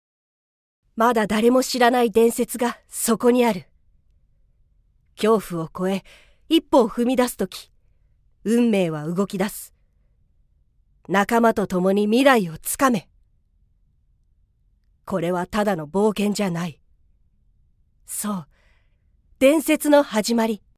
◆店内放送アナウンス(元気系)◆
◆作品宣伝CM(真面目)◆